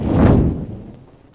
spawners_mobs_balrog_fireball.ogg